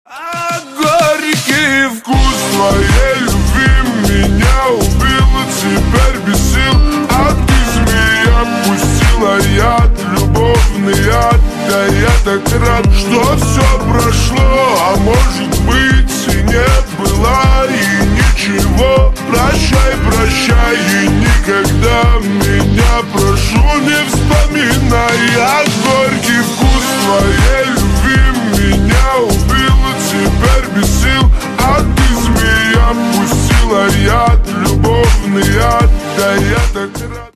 Громкие Рингтоны С Басами
Танцевальные Рингтоны